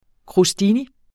Udtale [ kʁoˈsdini ]